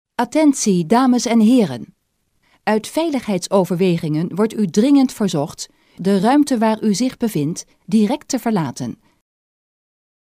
ontruimingstekst-dvh.mp3